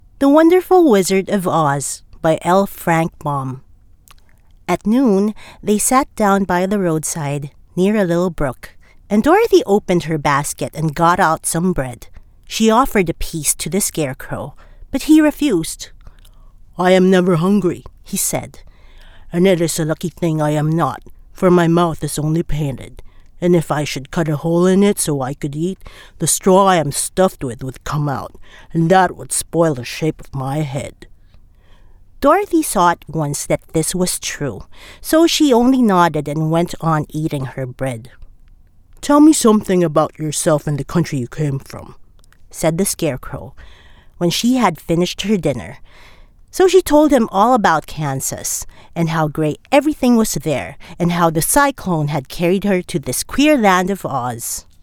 PH ENGLISH MALE VOICES
male